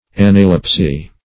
Search Result for " analepsy" : The Collaborative International Dictionary of English v.0.48: analepsis \an`a*lep"sis\ ([a^]n`[.a]*l[e^]p"s[i^]s), analepsy \an"a*lep`sy\ ([a^]n"[.a]*l[e^]p`s[y^]), [Gr.
analepsy.mp3